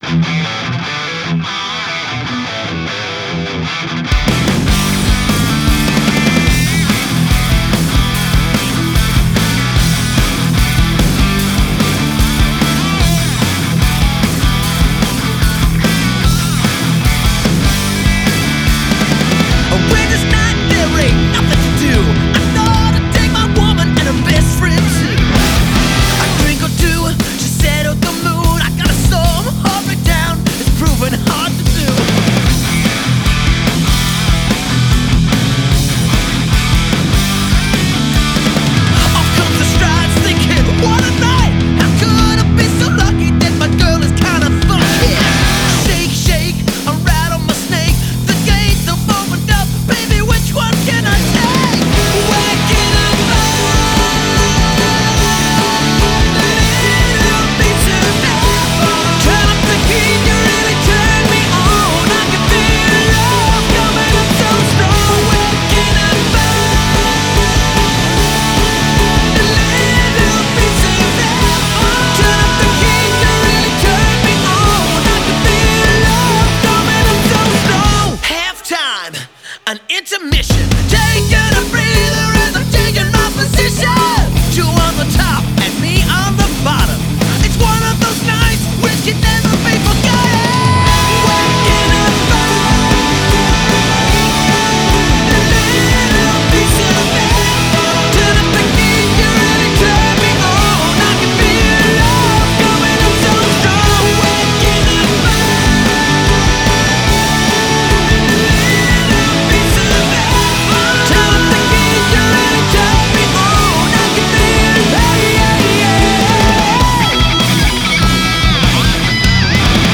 Australian rock band